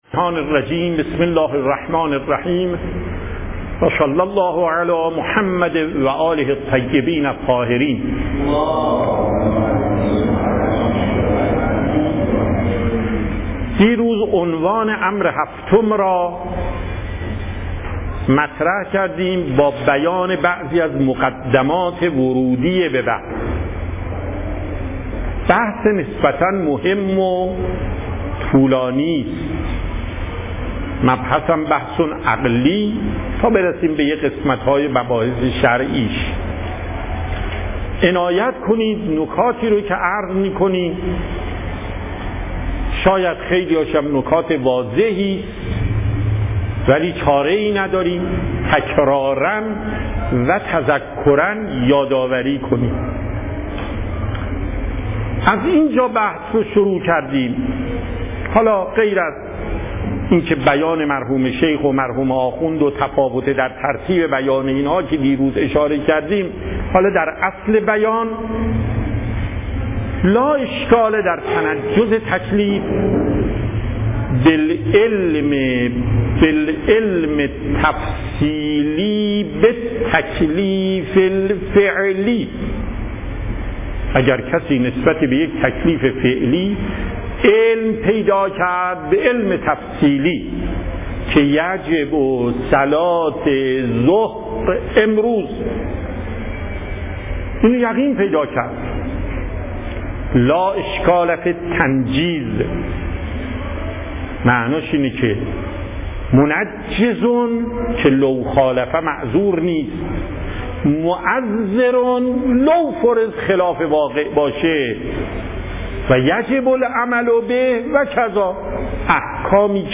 صوت درس
درس اصول آیت الله محقق داماد